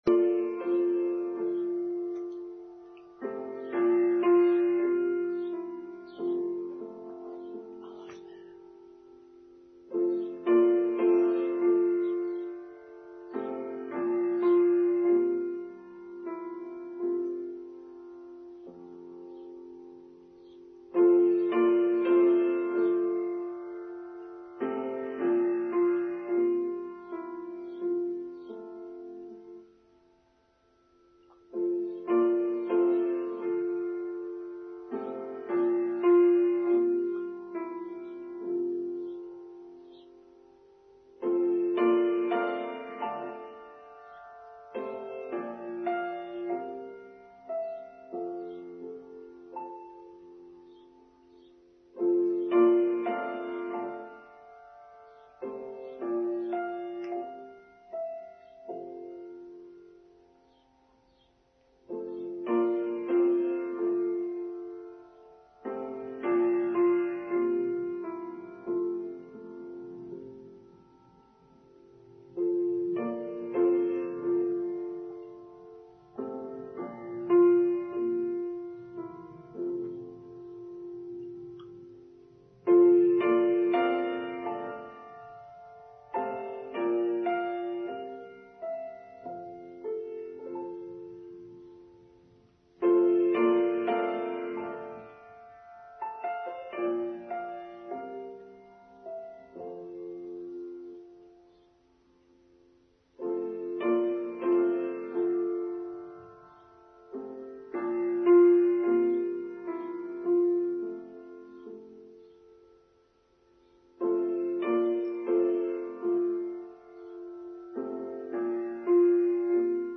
Respect: Online Service for Sunday 18th June 2023